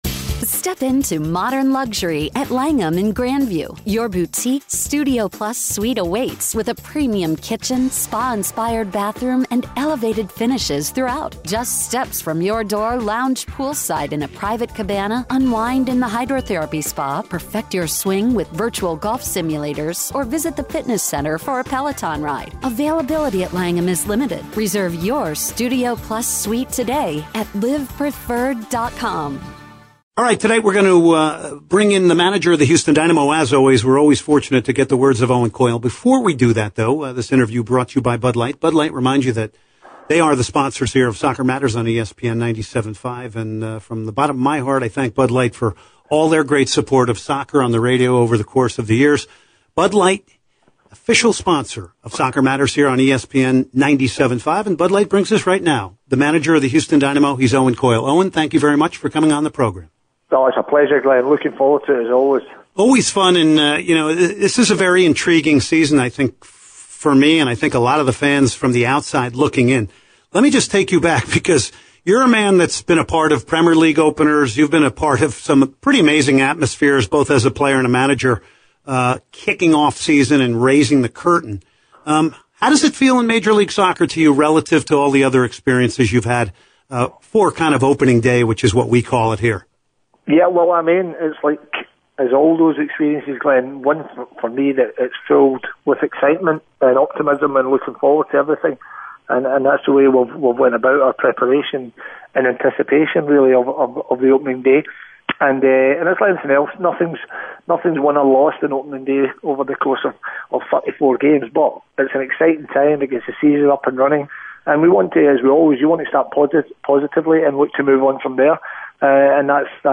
03/01/2016 Owen Coyle Interview